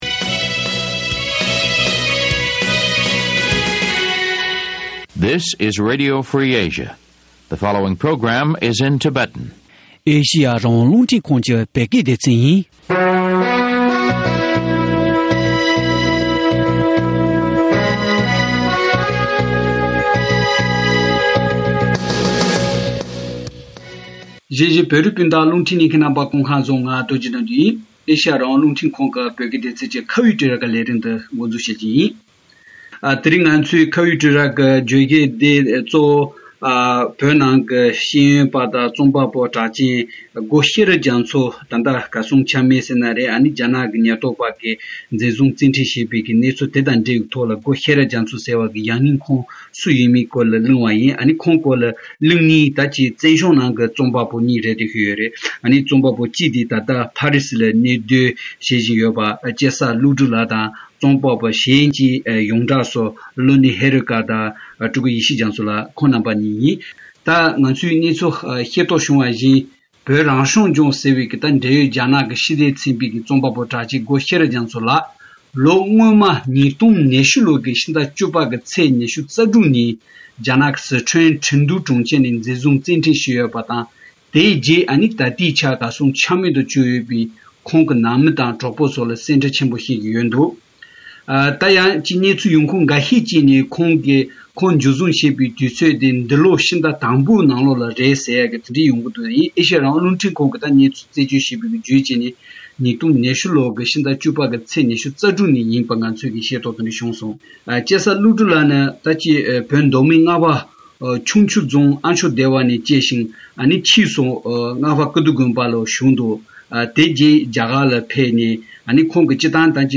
བཙན་བྱོལ་ནང་དུ་བོད་རིགས་རྩོམ་པ་གཉིས་དང་གླེང་མོལ་བྱས་པ།